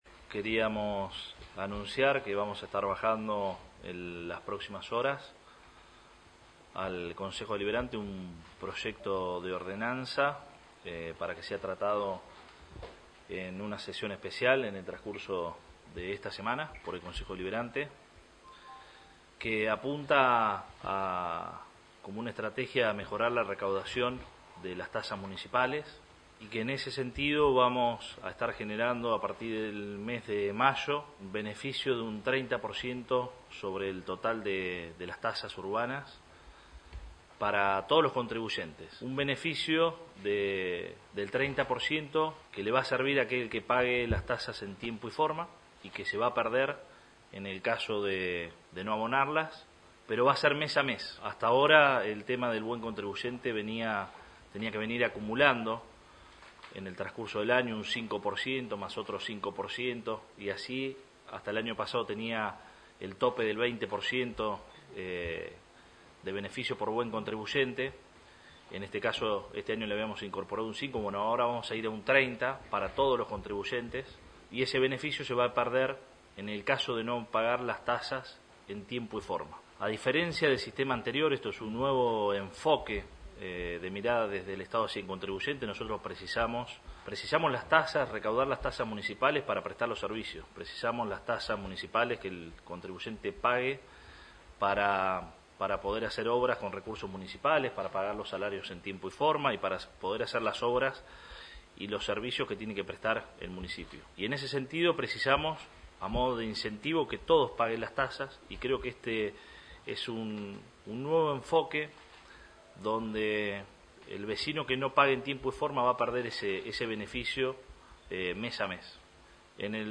“Para compensar las cuotas de marzo y abril que ya llegaron con el aumento, no vamos a liquidar el reajuste de enero y febrero” señaló el Intendente en el marco de una conferencia de prensa en su despacho
10-03-AUDIO-Arturo-Rojas.mp3